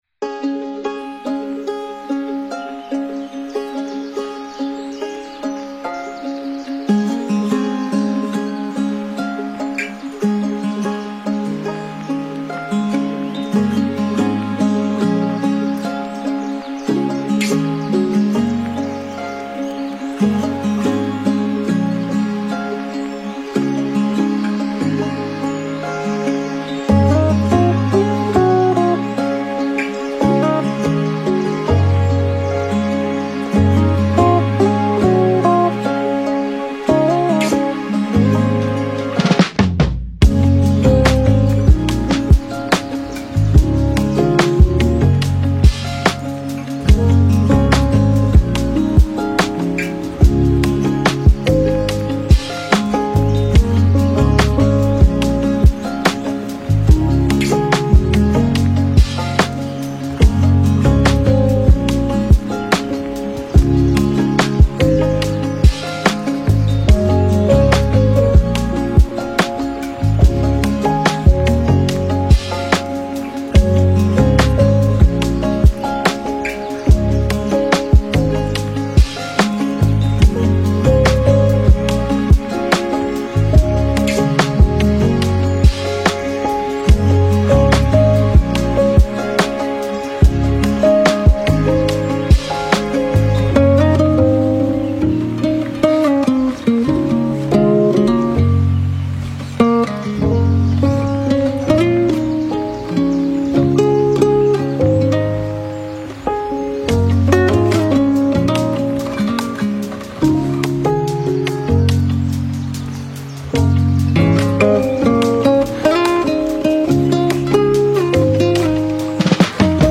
Мы подобрали для вас лучшие lo-fi песни без слов.
Летняя мелодия ☀